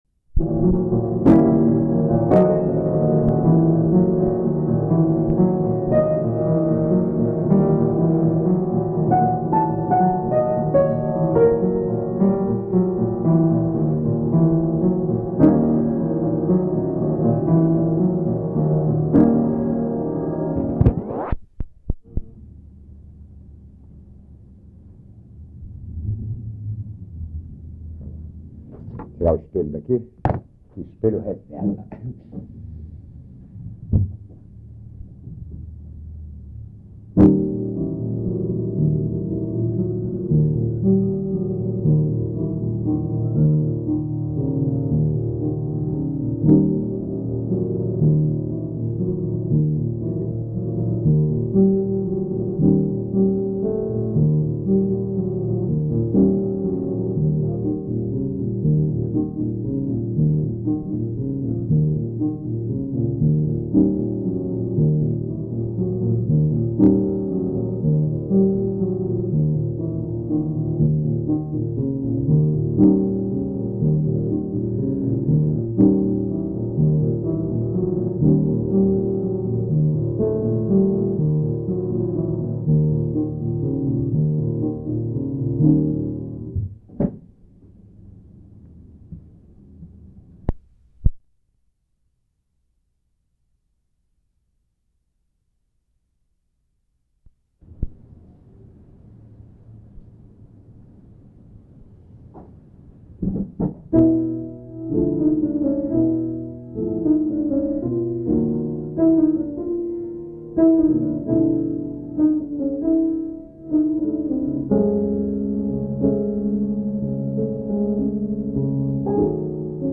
Type: Gravação musical Files in this item Files Size Format K7007ladoa.mp3 33.85Mb MPEG Audio This item appears in the following Collection(s) Home recordings [127] Fitas K7 Show full item record